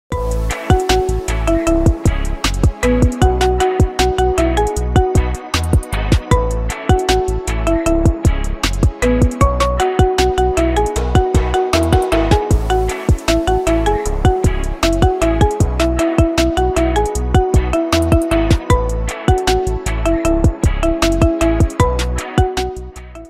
رنات ايفون الاصليه 2024